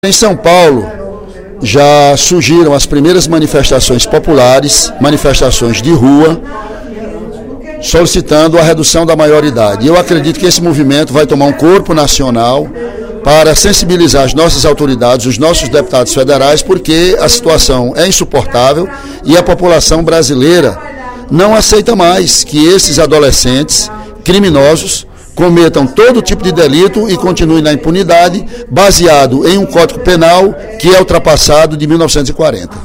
Durante o primeiro expediente da sessão plenária desta sexta-feira (14/06), o deputado Ely Aguiar (PSDC) voltou a criticar as posições contrárias à diminuição da maioridade penal no Brasil.